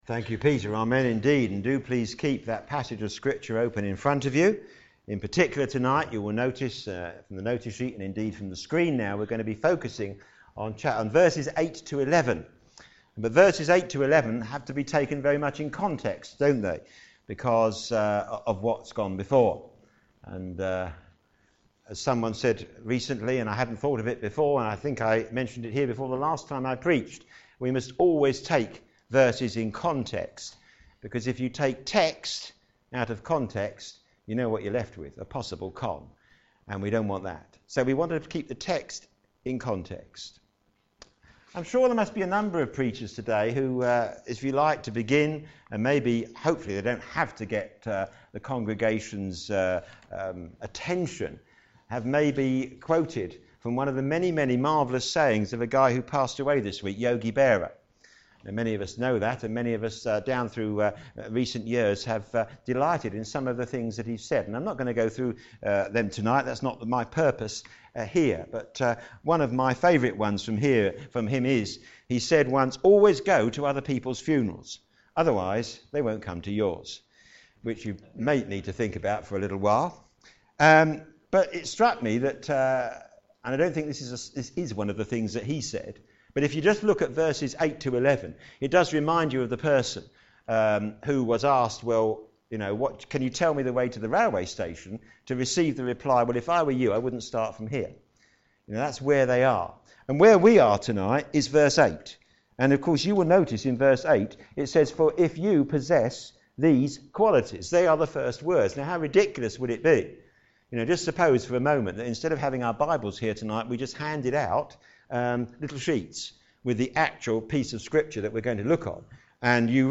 p.m. Service
Know your Salvation - Receives a Rich Welcome Sermon